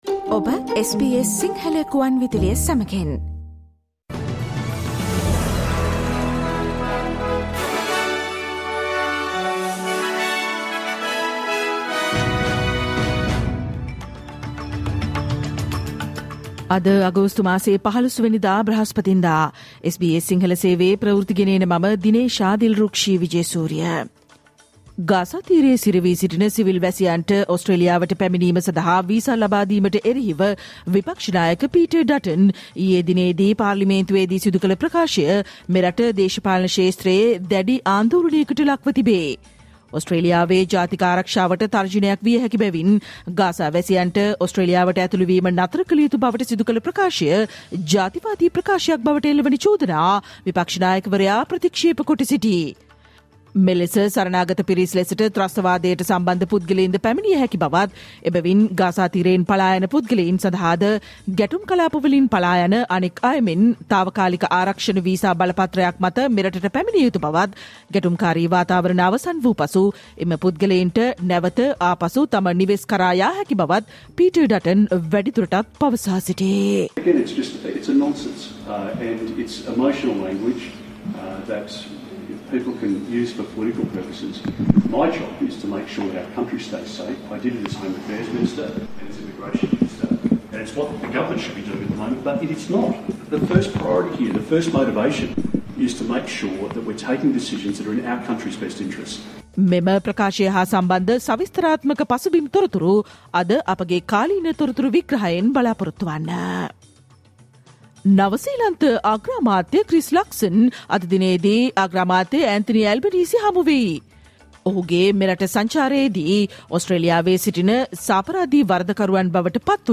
Australian news in Sinhala, foreign news, and sports news in brief, listen today, Thursday 15 August 2024 SBS Radio News